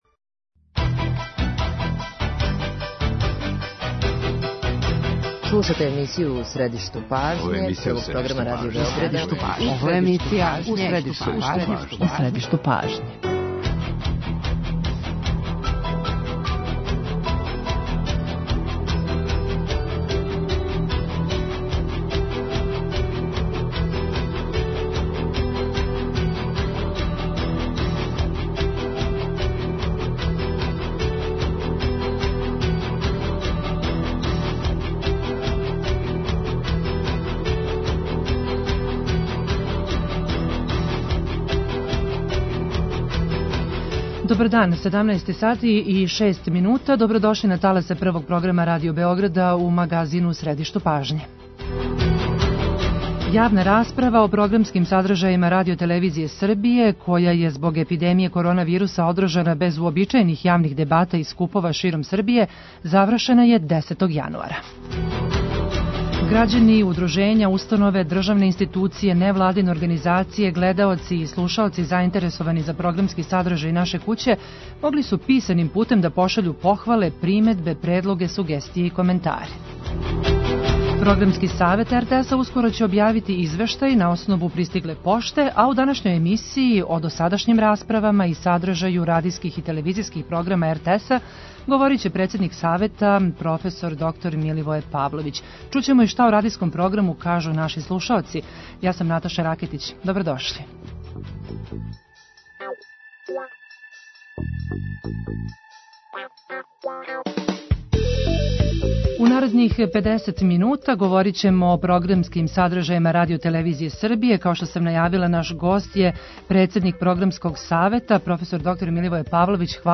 Чућемо и шта о радијском програму кажу наши слушаоци.